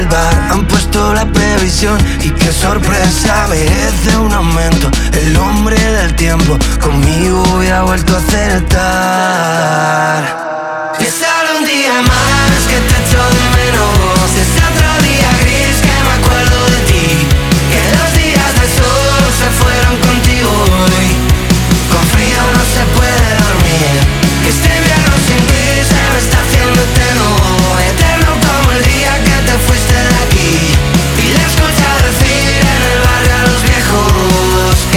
Alternative Indie Pop
Жанр: Поп музыка / Альтернатива